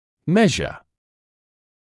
[‘meʒə][‘мэжэ]измерять, мерить; мера